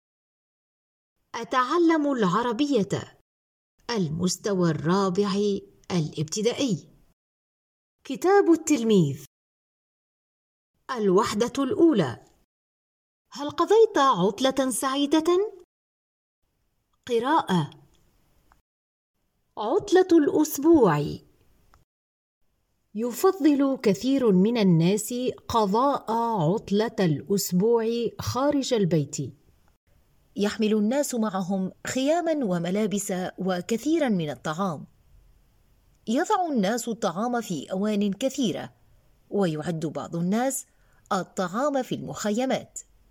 • Enregistrement audio de tout le contenu du manuel de l’élève